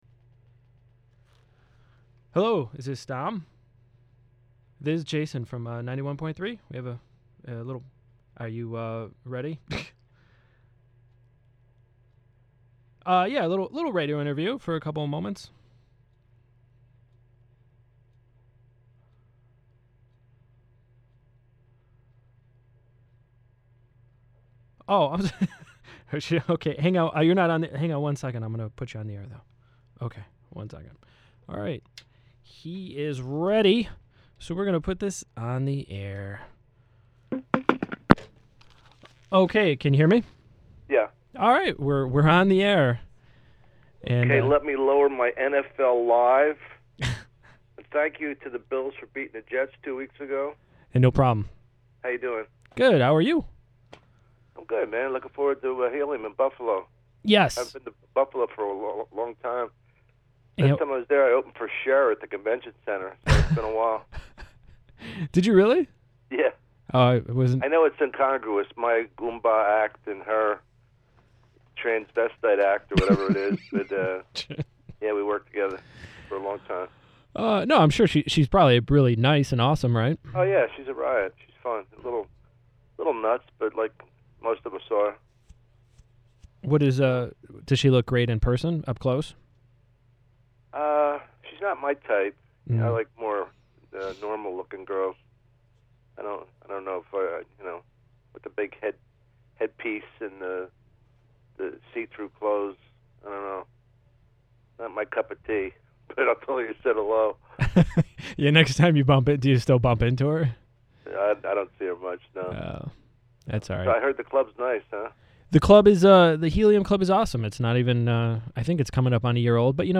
A conversation with comedian Dom Irrera